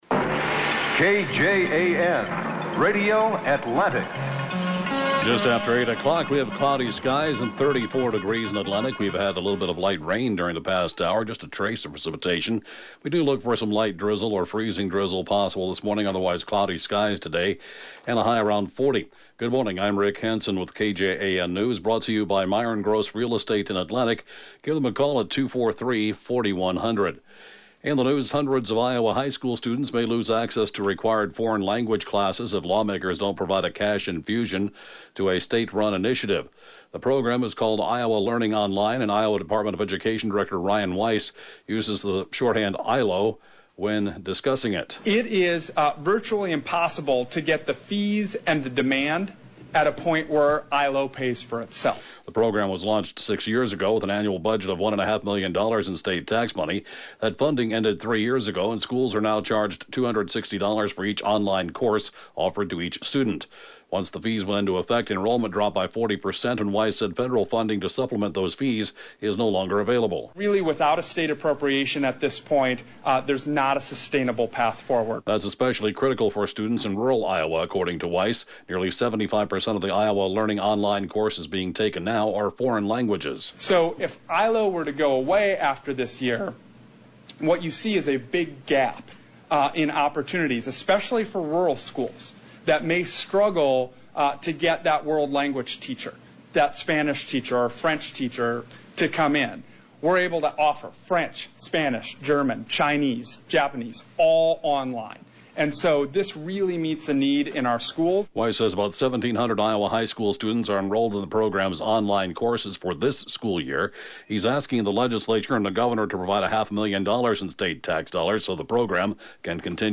(Podcast) KJAN 8-a.m. News, 1/31/2019